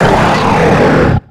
Cri de Drattak dans Pokémon X et Y.